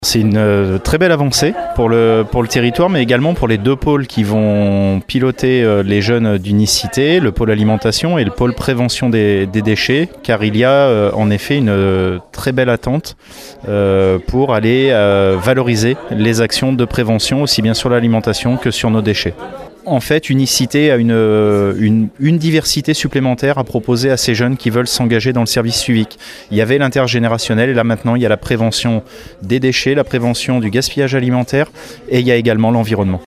Une aubaine pour le territoire de la Communauté d’Agglomération Royan Atlantique toujours en quête de solutions en faveur de l’environnement, comme le concède Éric Renoux, vice-président de la CARA en charge du Pôle écologie urbaine :